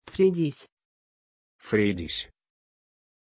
Aussprache von Freydis
pronunciation-freydis-ru.mp3